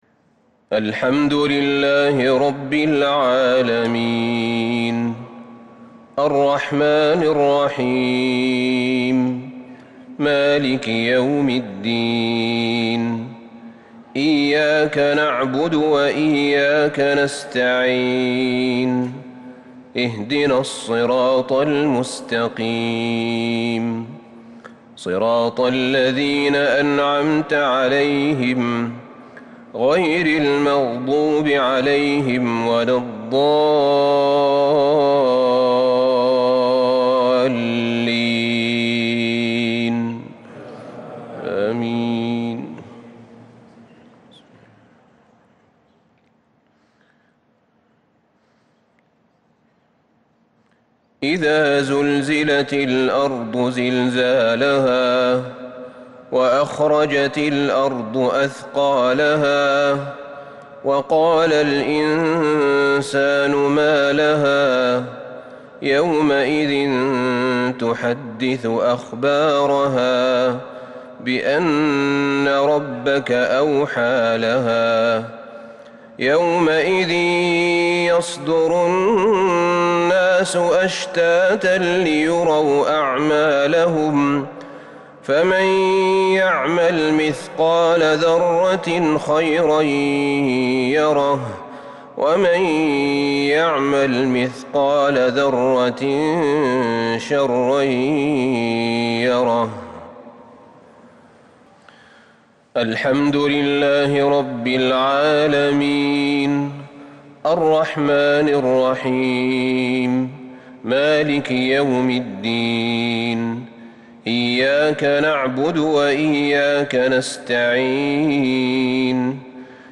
سورتي الزلزلة والعاديات | Maghrib prayer 6/4/2021 surah Az-Zalzala & al-`Adiyat > 1442 🕌 > الفروض - تلاوات الحرمين